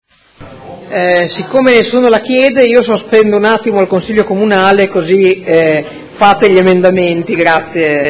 Seduta del 15/03/2018. Sospende lavori